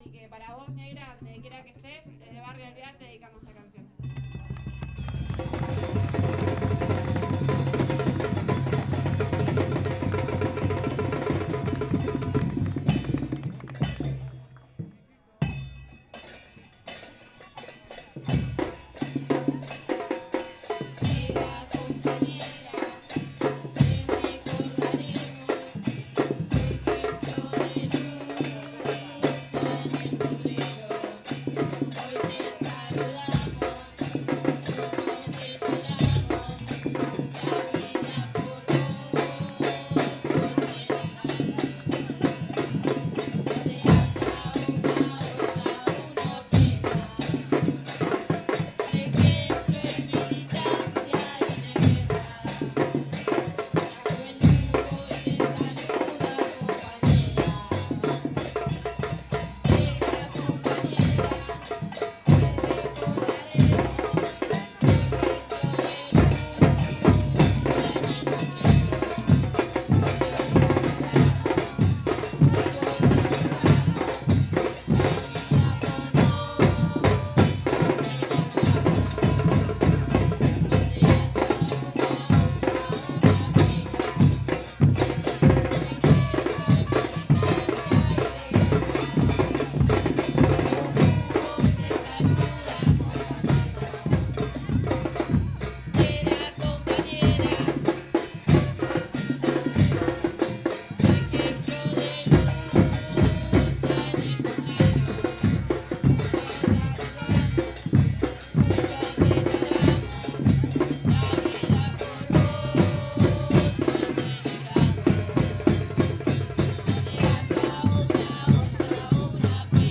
Carnaval Casa Uruguaya Rosario
psr-carnaval-15-candombe-hormiga-murga-aturdidos-de-evita.mp3